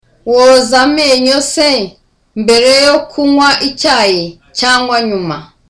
(Seriously)